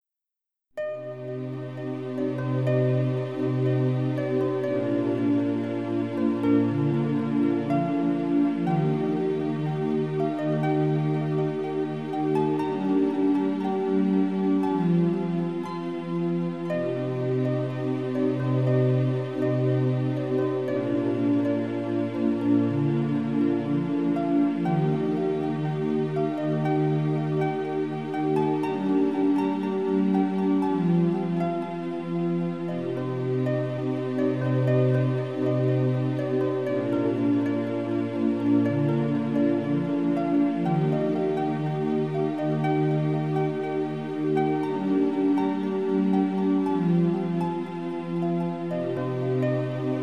Kolejna odsłona przepięknej muzyki relaksacyjnej.